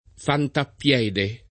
fantappiede [ fantapp L$ de ] → fante a piè